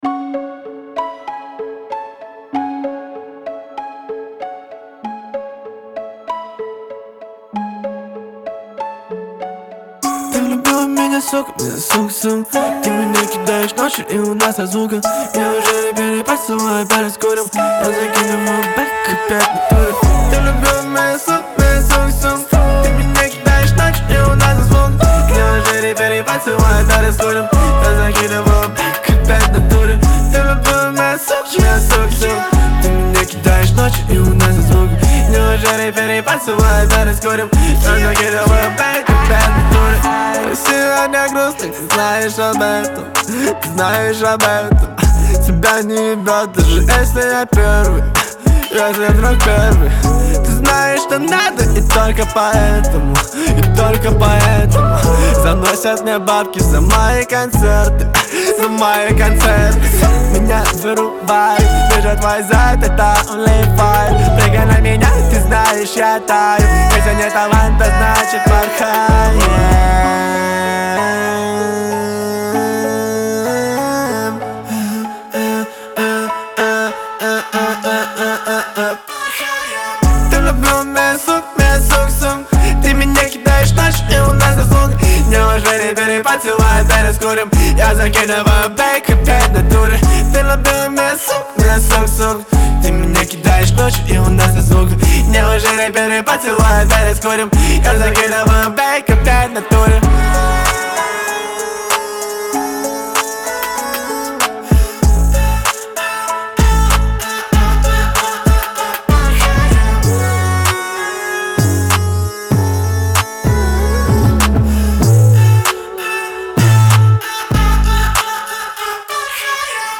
это энергичная и зажигательная песня в жанре поп